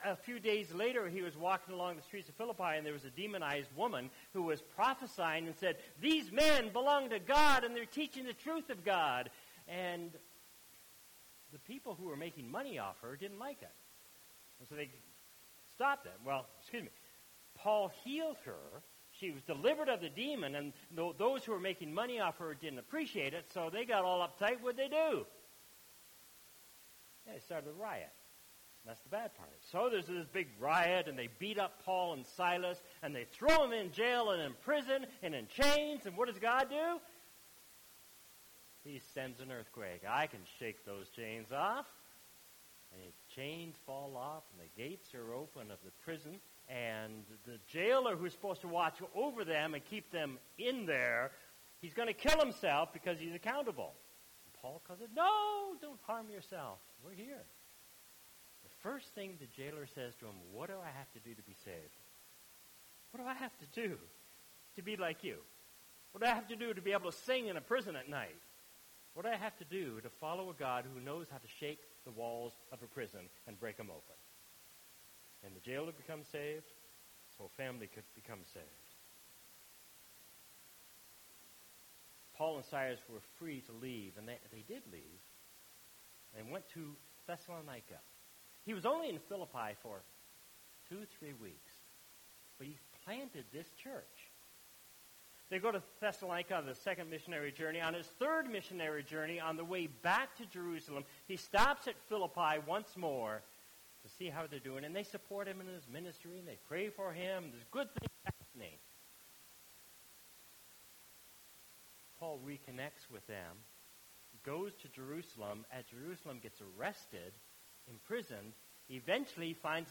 **NOTE: WE APOLOGIZE THAT THE FIRST FEW MINUTES OF THE MESSAGE WERE CUT OFF IN THE RECORDING.